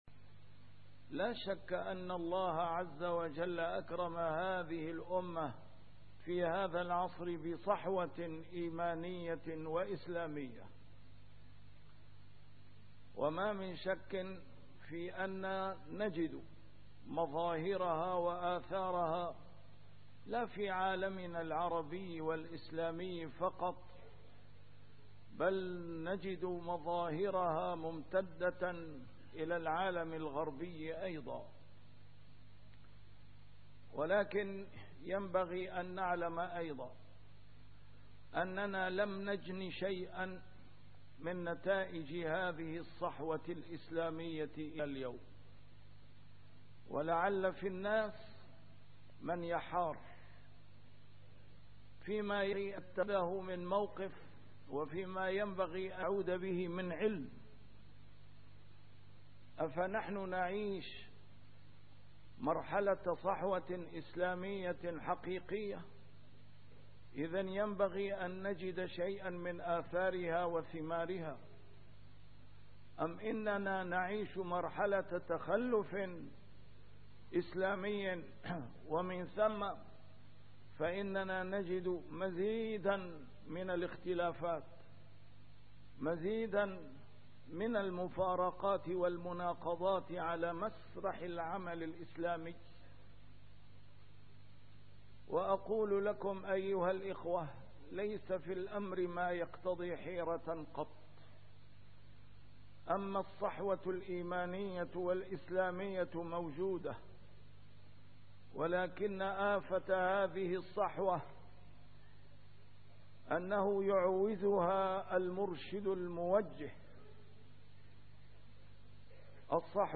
A MARTYR SCHOLAR: IMAM MUHAMMAD SAEED RAMADAN AL-BOUTI - الخطب - المرشد الرباني .. صفاته؟ وأهميته؟